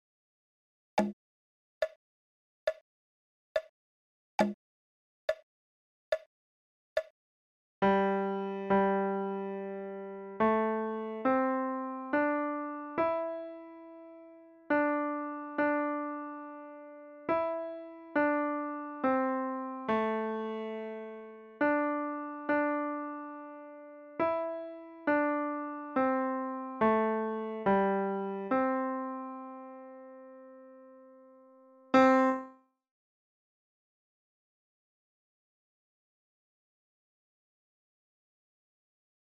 Goes--no staff, white keys